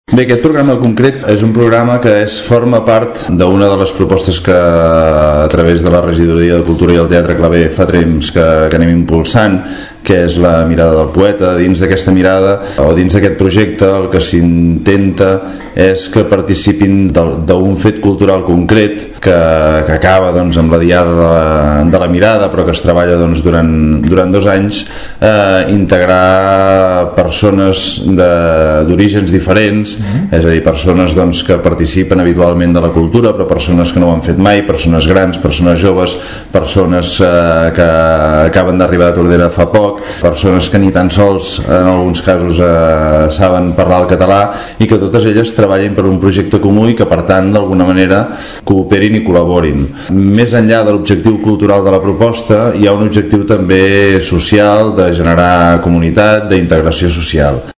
El programa té entre els seus objectius incorporar col·lectius en risc d’exclusió social en la pràctica de la formació artística i sensibilització en l’art. Segons el regidor de cultura, Josep Llorens l’iniciativa es fa a través del projecte la Mirada del poeta, que impulsa la fundació del Teatre Clavé.